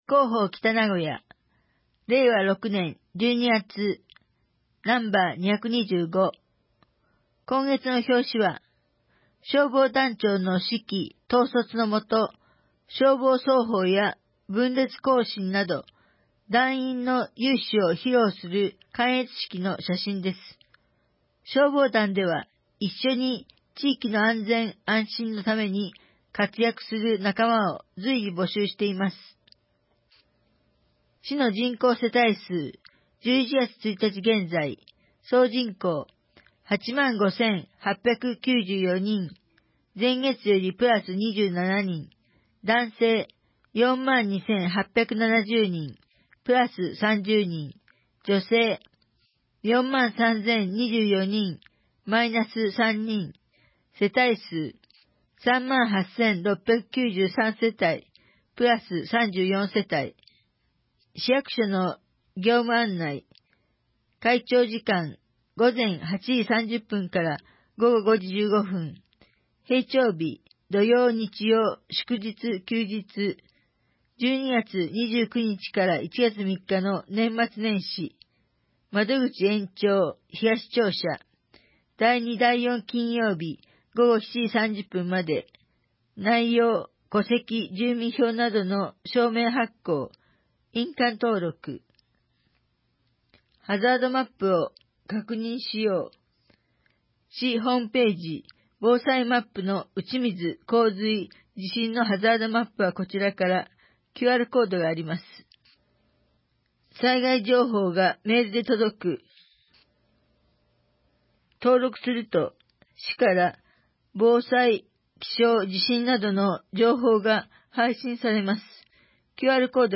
2024年12月号「広報北名古屋」音声版